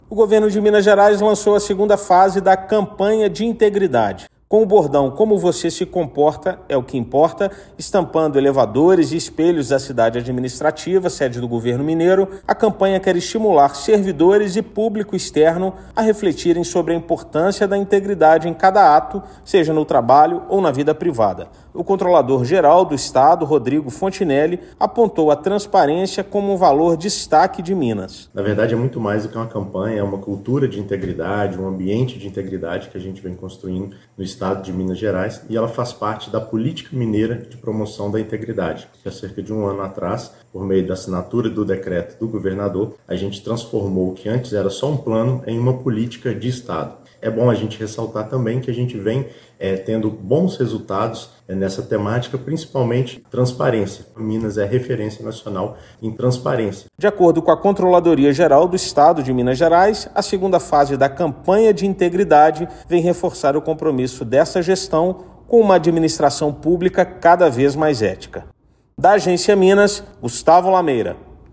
Ética, Integridade e Agenda ESG alinham-se pela melhor gestão da Administração Pública. Ouça matéria de rádio.